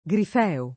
[ g rif $ o ]